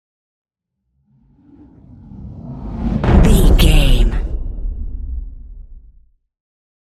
Cinematic whoosh to hit deep
Sound Effects
Atonal
dark
intense
tension
woosh to hit